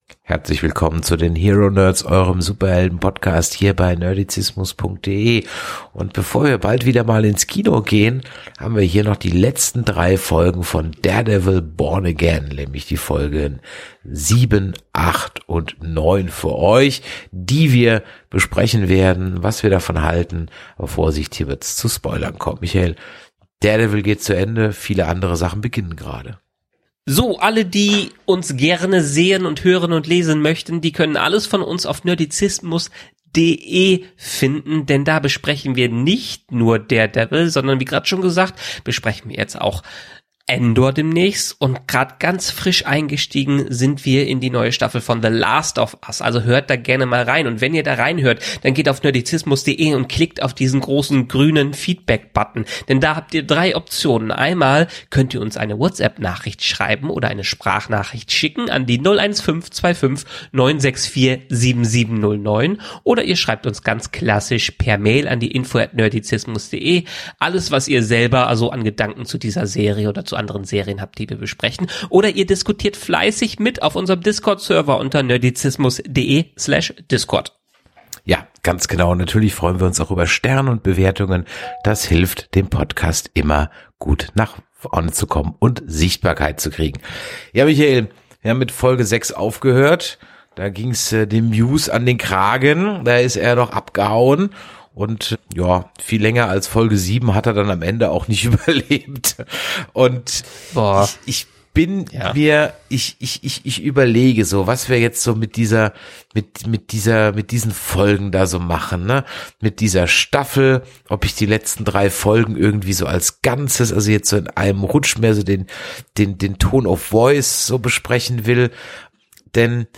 Warum Muse und Bullseye verschenktes Potenzial sind, welche Lichtblicke trotzdem Hoffnung machen – und wieso die nächste Staffel die Chance hat, alles zu retten –, erfahrt ihr im knackigen Nerd-Talk.